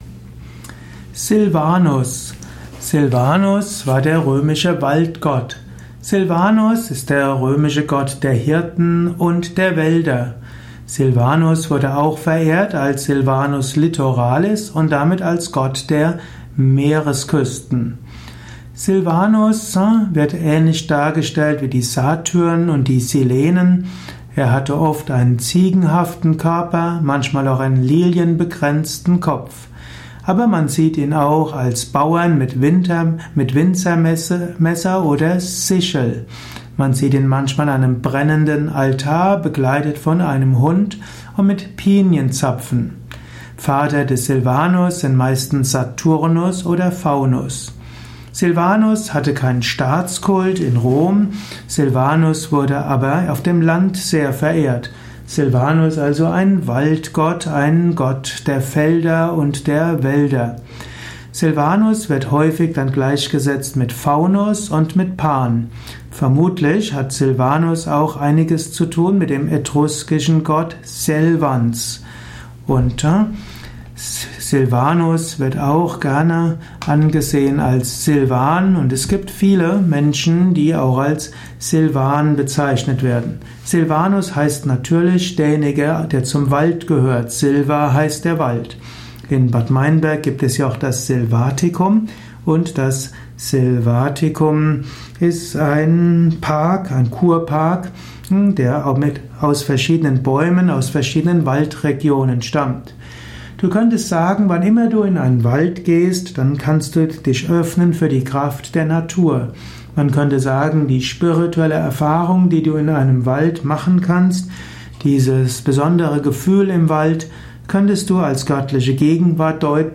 Ein Audio Vortrag über Silvanus, einem römischen Gott. Ausführungen über die Stellung von Silvanus in der römischen Mythologie, im römischen Götterhimmel.
Dies ist die Tonspur eines Videos, zu finden im Yoga Wiki.